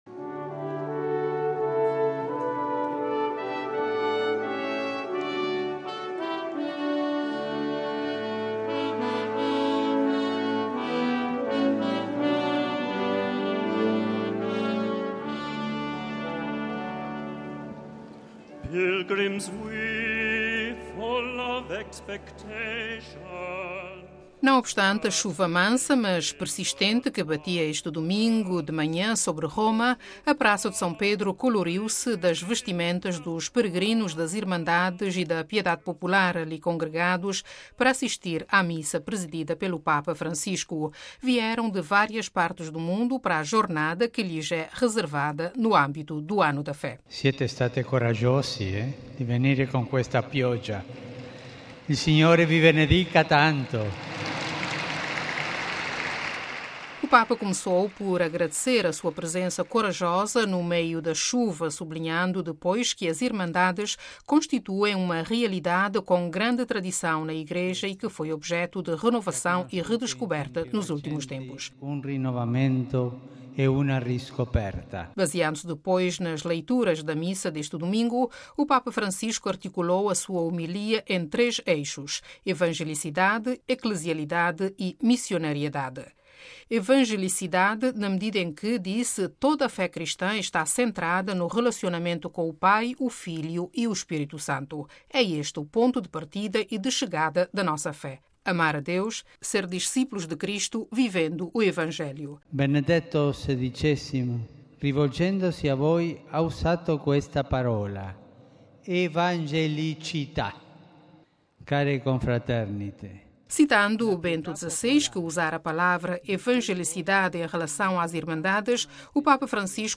Evangelicidade, eclesialidade, missionariedade - eixos da homilia do Papa na Missa com as Irmandades na Praça de São Pedro